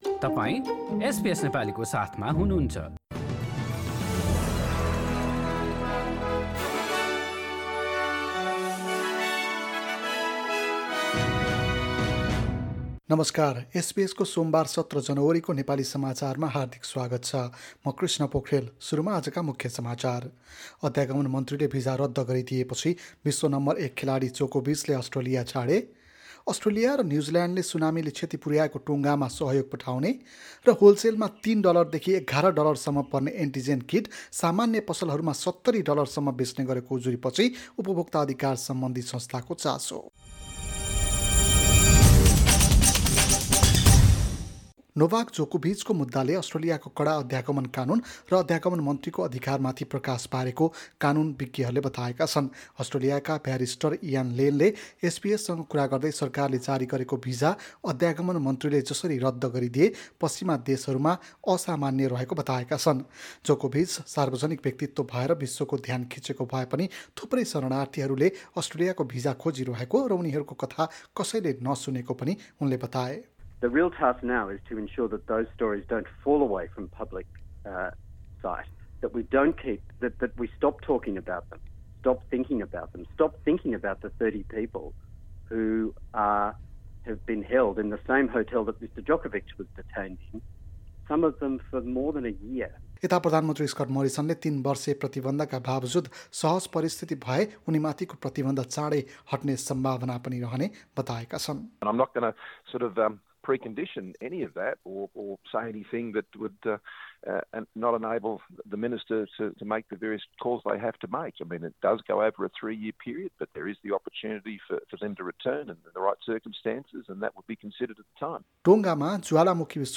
एसबीएस नेपाली अस्ट्रेलिया समाचार: सोमबार १७ जनवरी २०२२